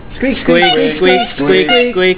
The procession rolled on, spinning front
squeak.au